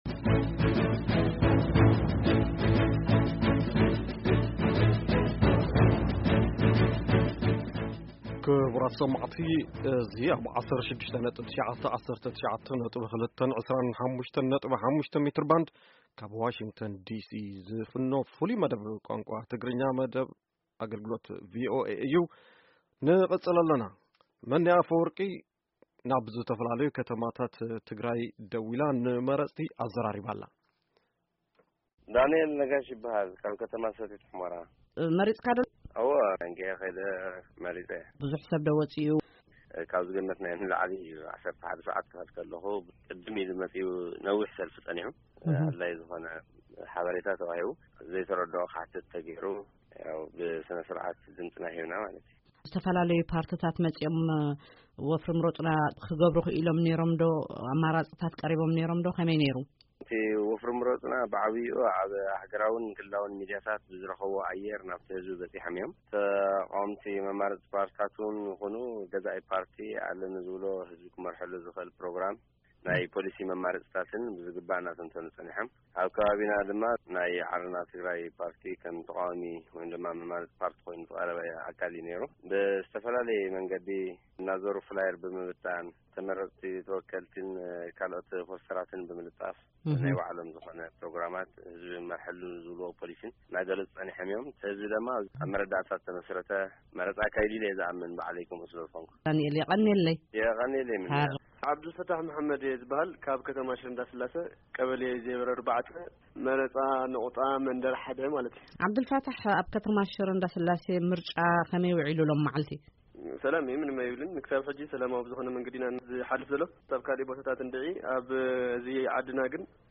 ካብ ሰቲት ሑመራ፣ ኣኽሱም ፣ ሽረ እንዳስላሴ፣ ዓዲ ግራትን ኣላማጣን ብቀጥታ ብስልኪ ሬድዮ ድምፂ ኣሜሪካ ዘዘራረቦም ነበርቲ ዝተፈላለየ ሪኢቶ ሂቦም'ለው።